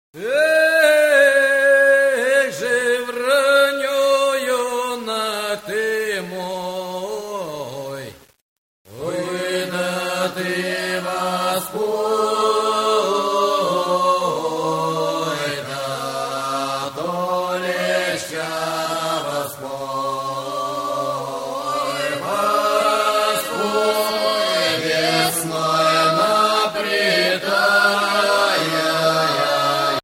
- Traditional songs of Cossacks
vocal, solo, Gudok (fiddle), drum, perc